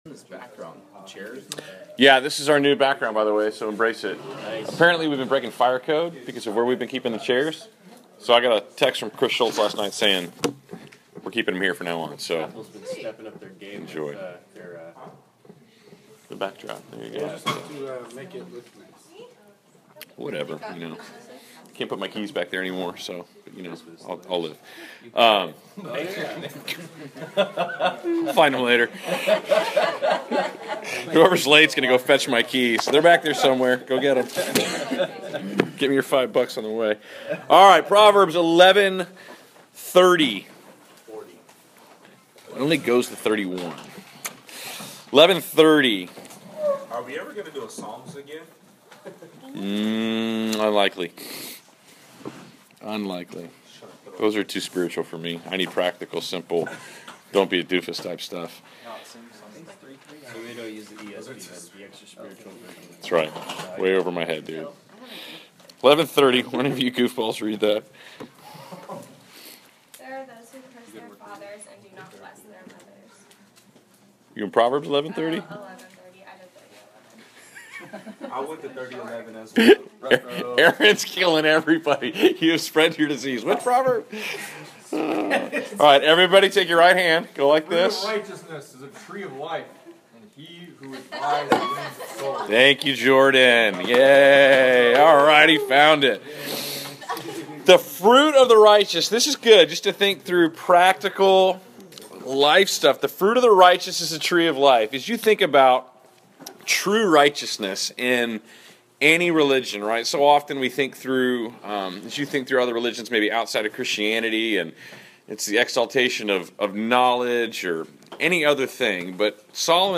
Class Session Audio March 03